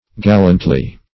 Gallantly \Gal*lant"ly\, adv.
Gallantly \Gal"lant*ly\, adv.
gallantly.mp3